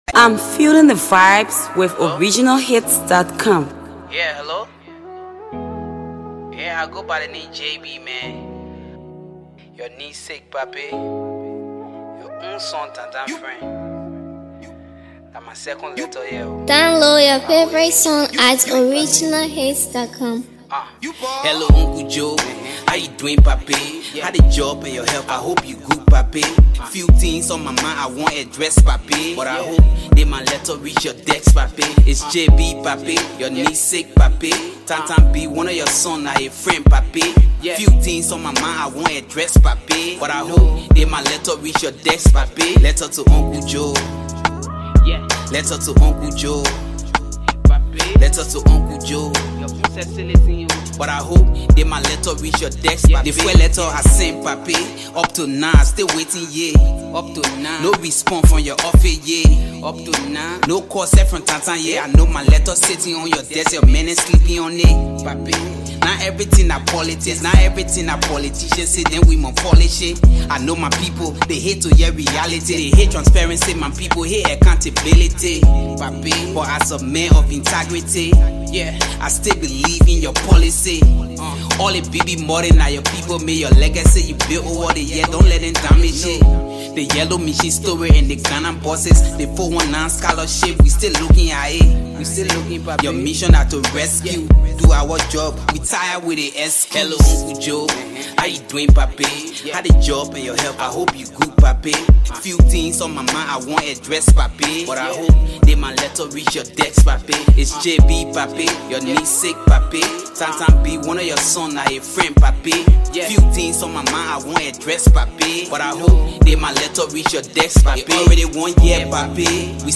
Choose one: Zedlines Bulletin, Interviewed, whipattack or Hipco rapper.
Hipco rapper